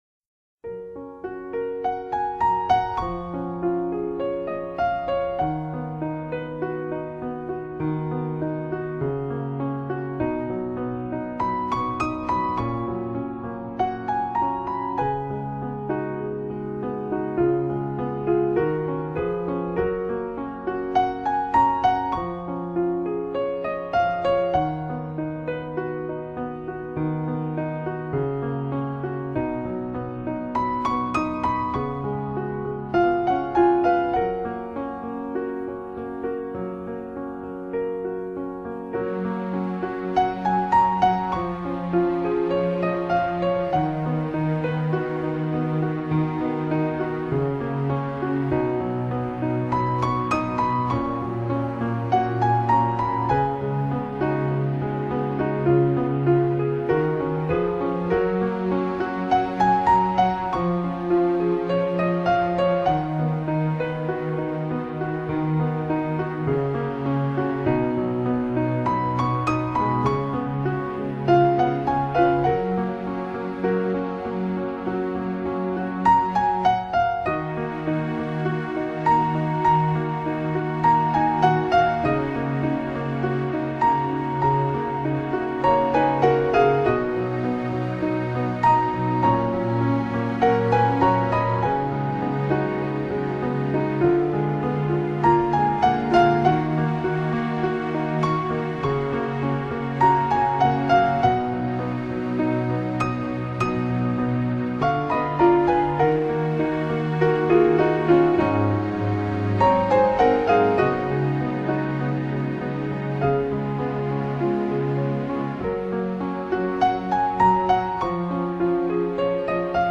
Genre: New Age, Solo Piano, Neoclassical